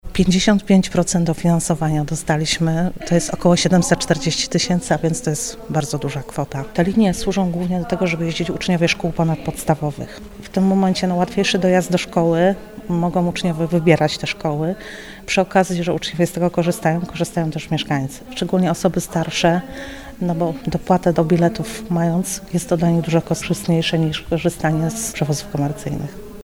– Możemy przeznaczyć pieniądze na dopłaty do biletów – informuje Wioletta Efinowicz, Starosta Powiatu Oleśnickiego.